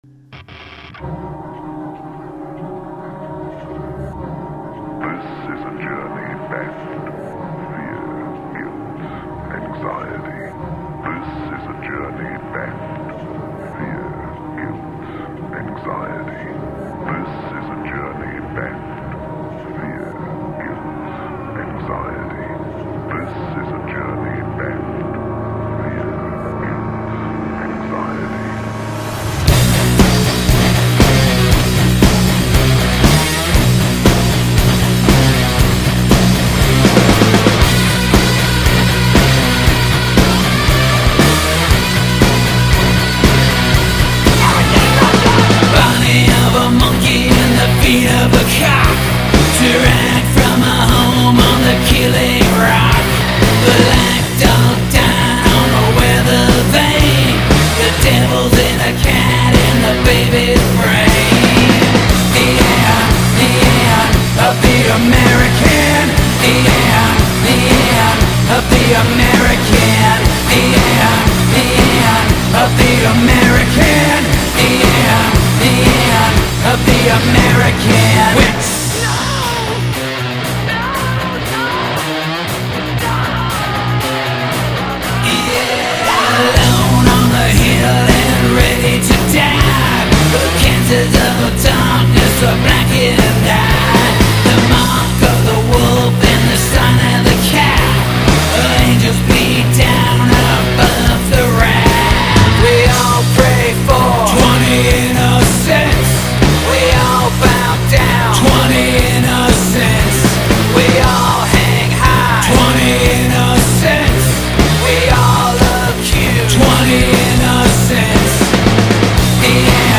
هوی متال